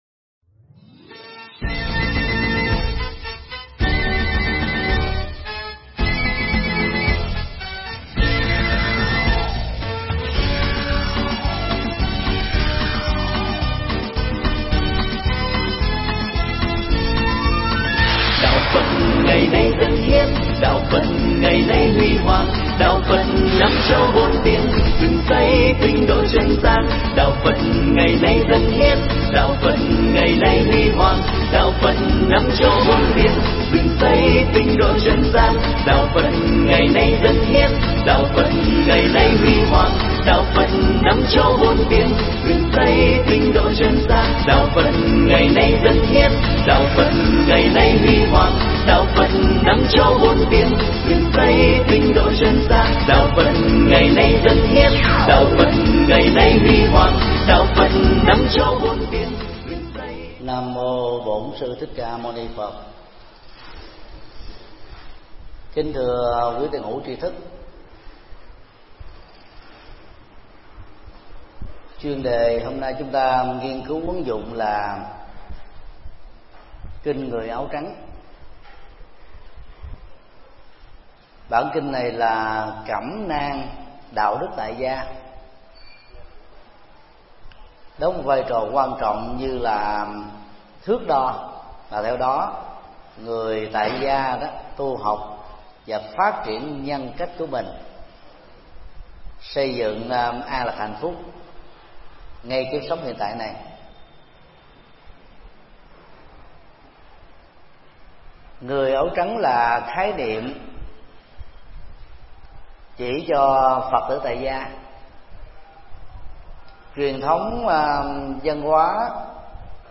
Tải mp3 Bài giảng Kinh Người Áo Trắng: Năm Điều Đạo Đức do thầy Thích Nhật Từ giảng tại chùa Xá Lợi, ngày 27 tháng 04 năm 2013